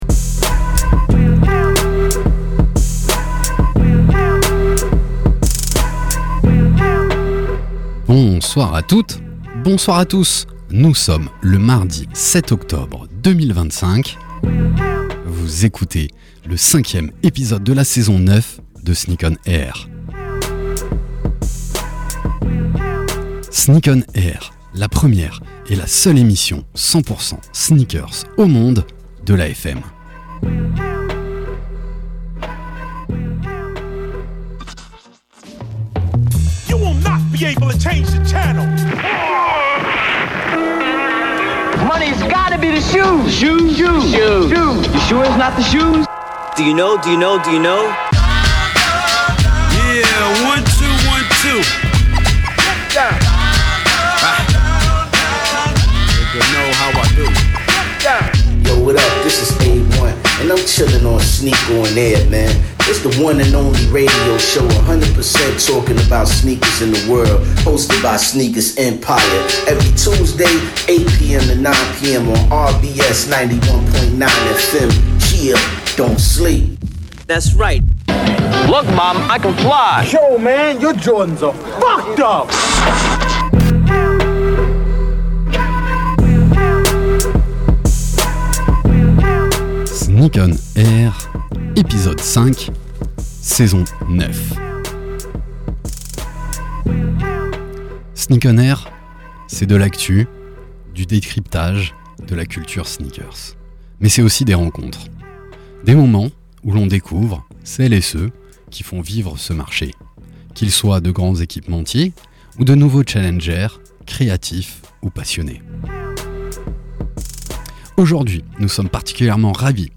Sneak ON AIR, la première et la seule émission de radio 100% sneakers au monde !!! sur la radio RBS tous les mardis de 20h à 21h. Animée par l’équipe de Sneakers EMPIRE. Actu sneakers, invités, SANA, talk.